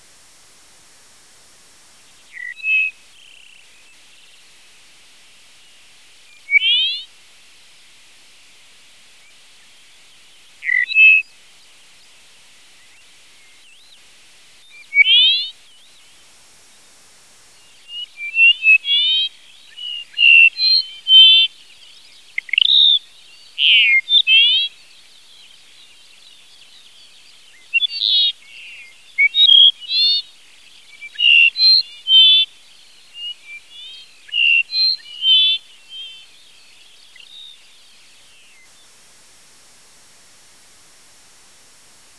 This location could have been the scene in Killian Mullarney's painting, so closely did the habitat of flat sandy ground with tussocks of grass and small rocks resemble the picture in the Collins Bird Guide.
Faintly but clearly, the distinctive, melancholy song was drifting our way, sliding mournfully up and down between notes